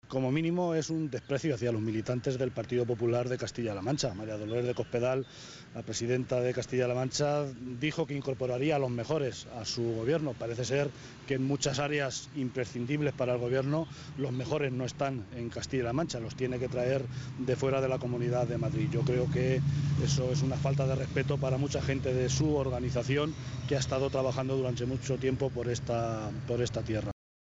José Luis Martínez Guijarro, portavoz del Grupo Parlamentario Socialista.
Cortes de audio de la rueda de prensa